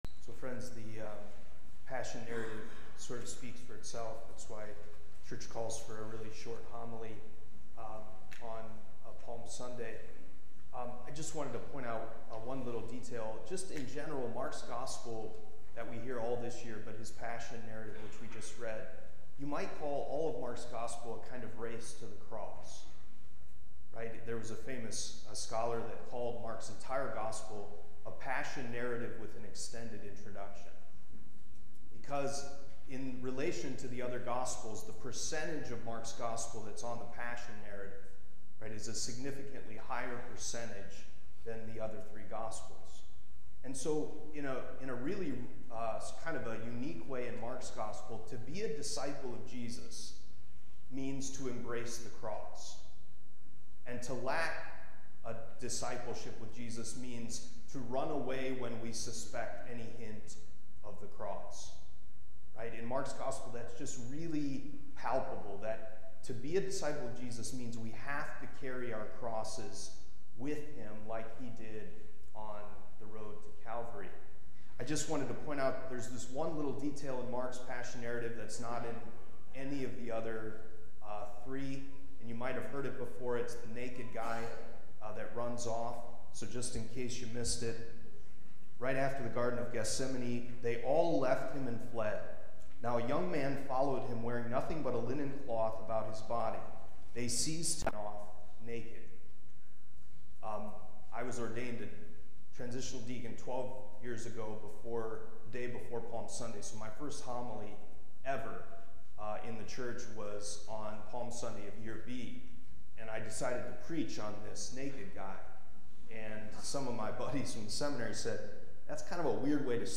Homily from Palm Sunday, March 24, 2024.